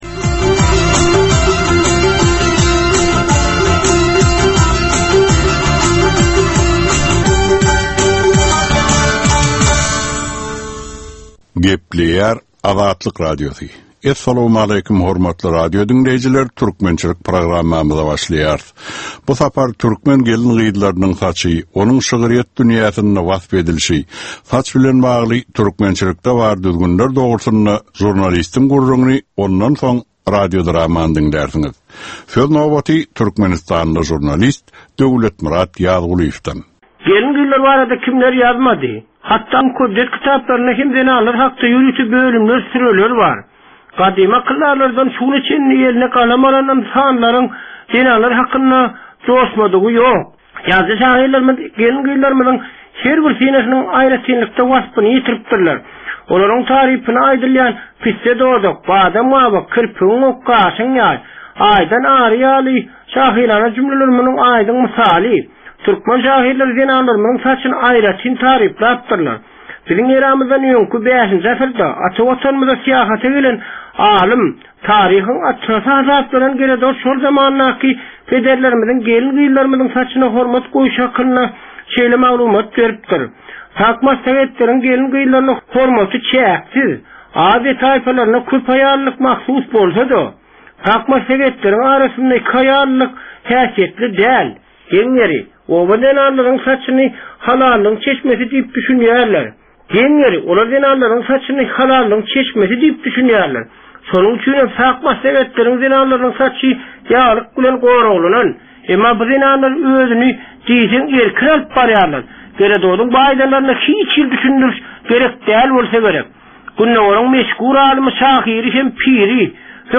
Türkmen halkynyn däp-dessurlary we olaryn dürli meseleleri barada 10 minutlyk ýörite geplesik. Bu programmanyn dowamynda türkmen jemgyýetinin su günki meseleleri barada taýýarlanylan radio-dramalar hem efire berilýär.